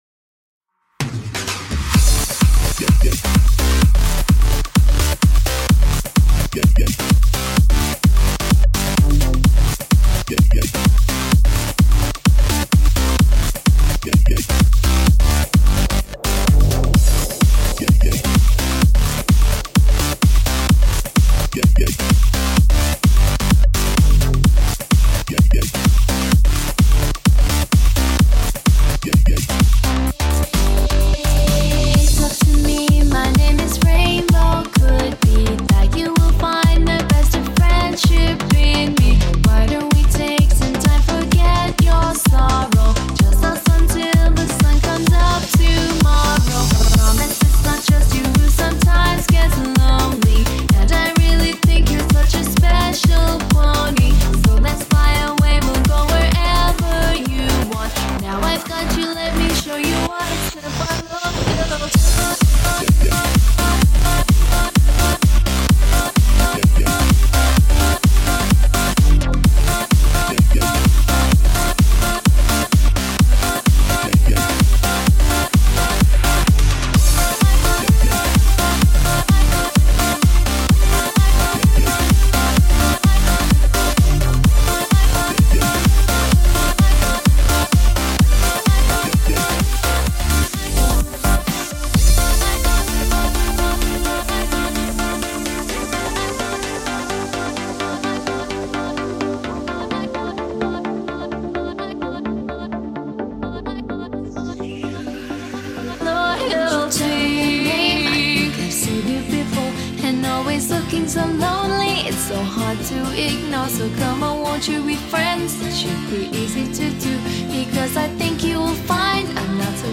With 128 BPM it's the slowest EDM track I've ever done.